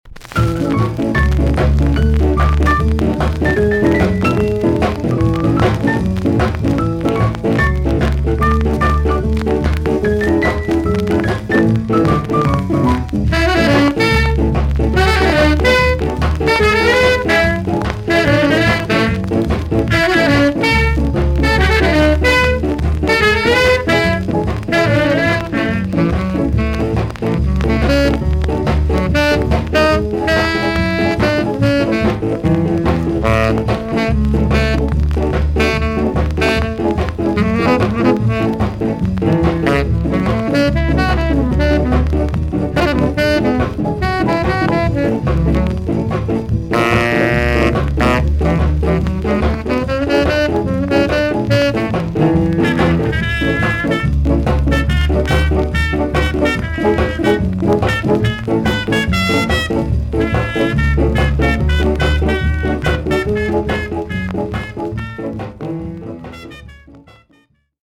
TOP >SKA & ROCKSTEADY
VG ok 全体的にチリノイズが入ります。